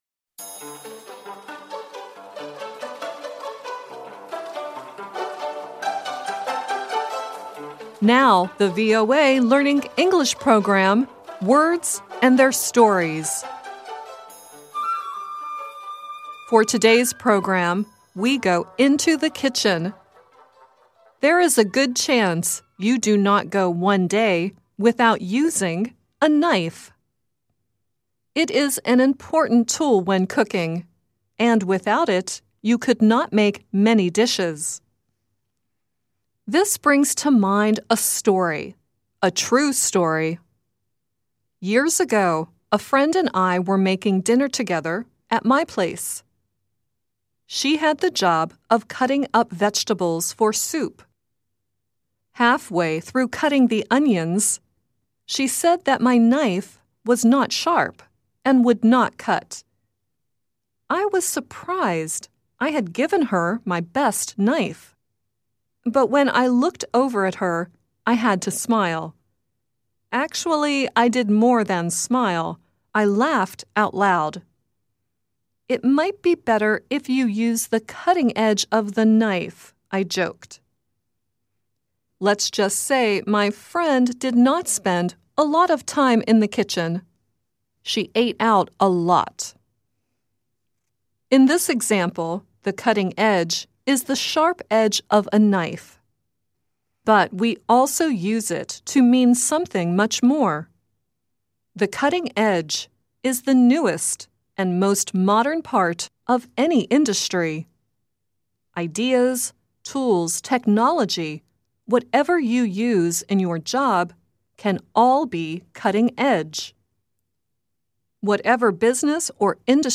At the end of the program, Jennifer Warnes sings The Panther.